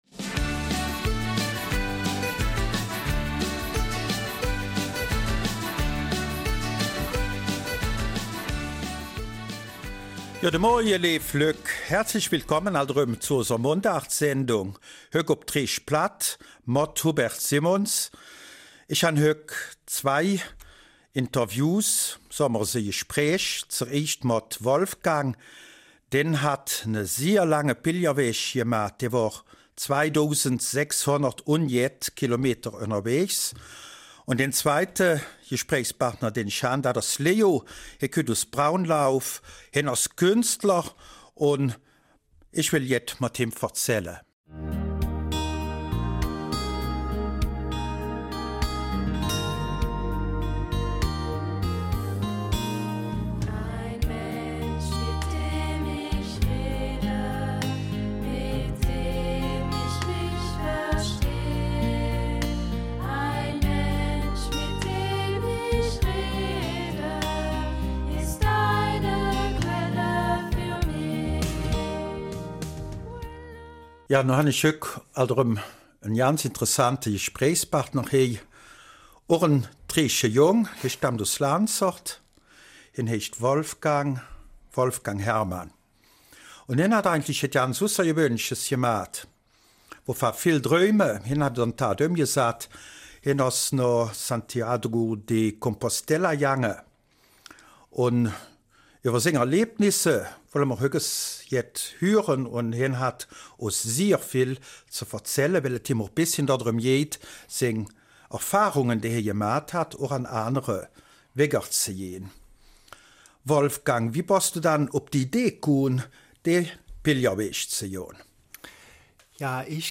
Des weiteren ein Gespräch mit einem Künstler, der auch im BRF Fernsehen zu sehen war.